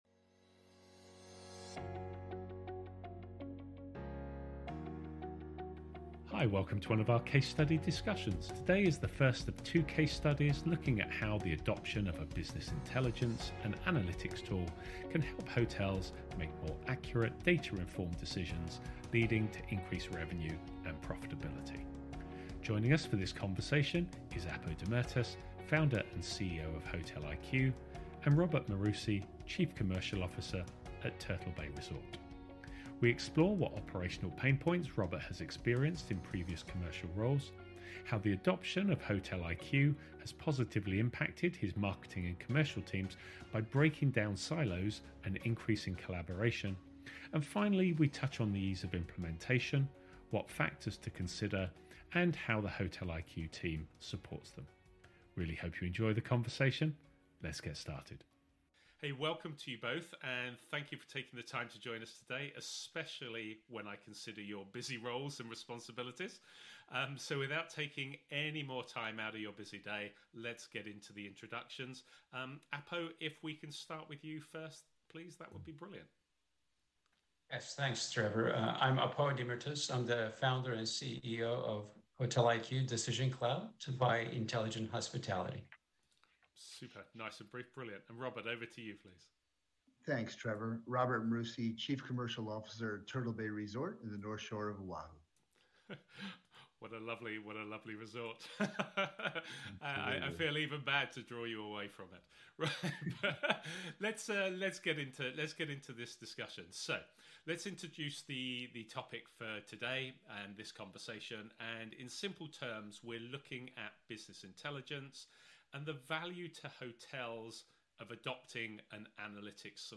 Hi, welcome to one of our case study discussions.